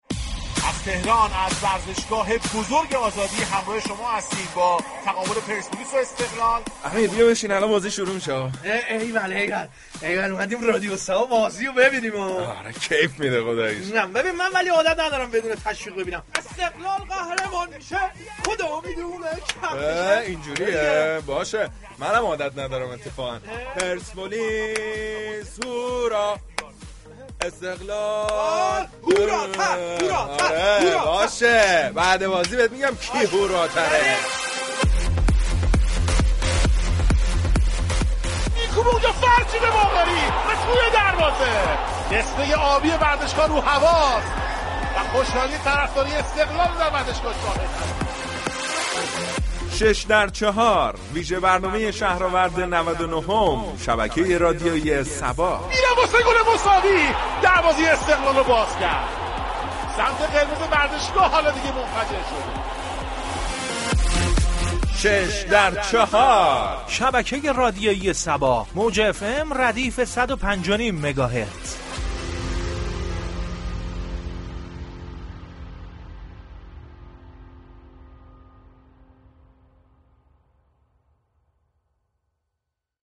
ویژه برنامه «شش در چهار» با پخش كری خوانی قرمز و آبی ها، شهرآورد نودونهم را با نگاهی طنز برای مخاطبان گزارش می كنند.
این برنامه روز سه شنبه 29 آذر با توجه به بازی شهرآورد استقلال وپرسپولیس به صورت ویژه همراه مخاطبان می شود، كه بازیگران و مجریان این برنامه در فضایی شاد با گزارش طنز این بازی به كری خوانی قرمز و آبی های فوتبالی می پردازد.